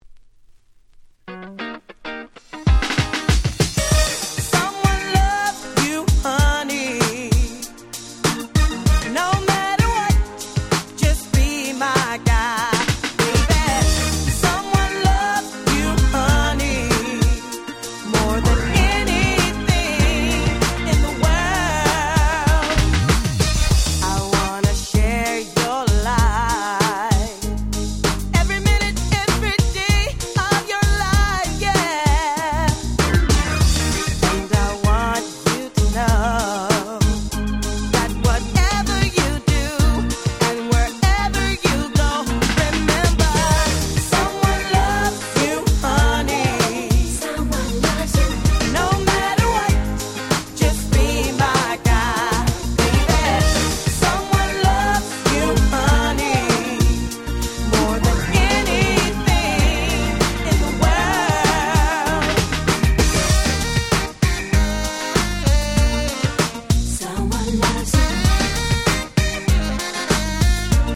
90's 00's R&B キャッチー系 Dance Pop ダンスポップ